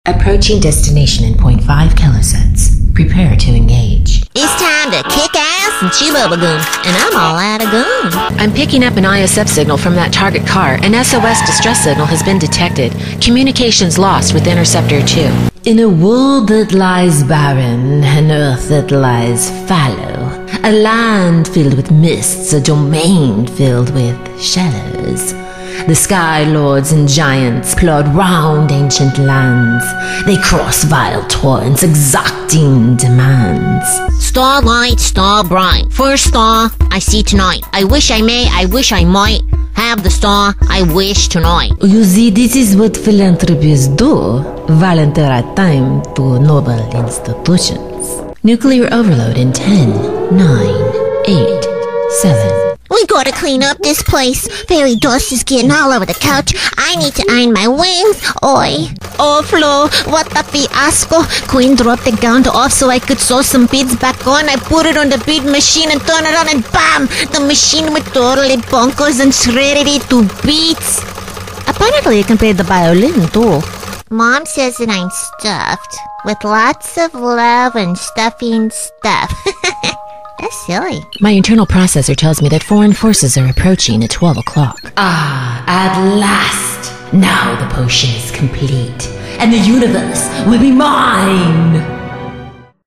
Adult, Mature Adult
Has Own Studio
standard us | natural
ANIMATION 🎬